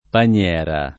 paniera [ pan L$ ra ] s. f.